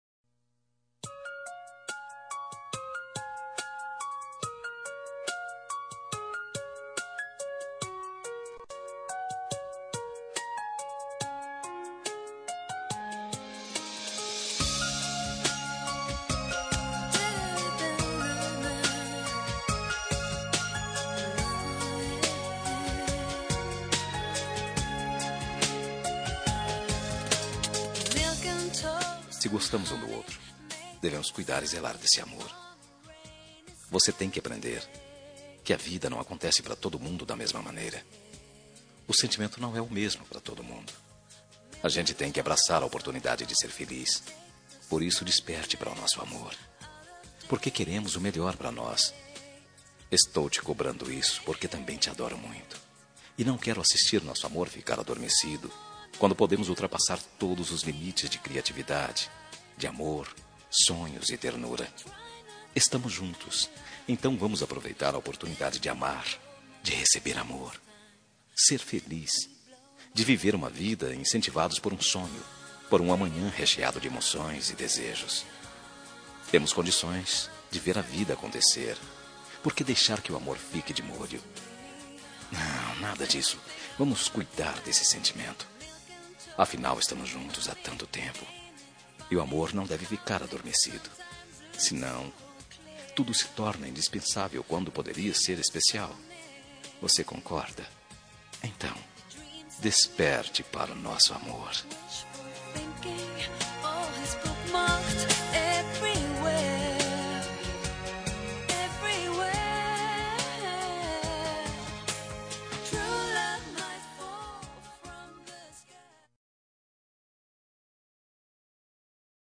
Telemensagem Relacionamento Crise – Voz Masculina – Cód: 5444